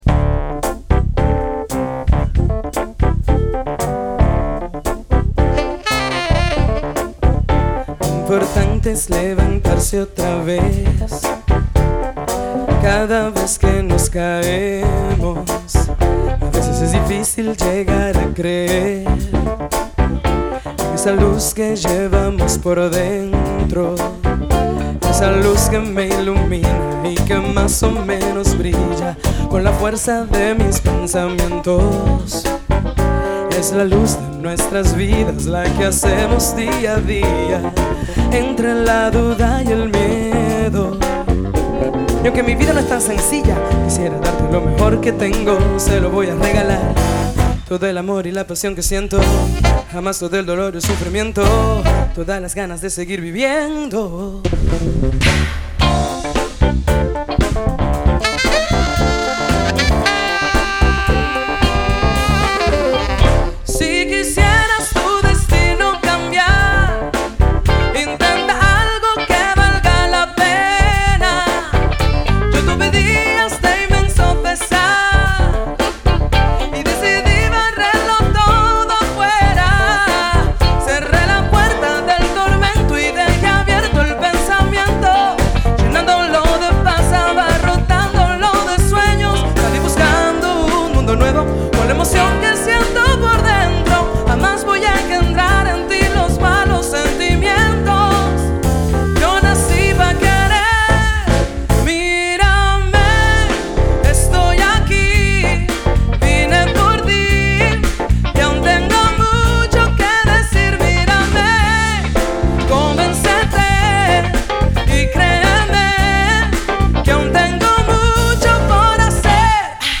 vocal, piano
saxophones
percussions
basse électrique, contrebasse
batterie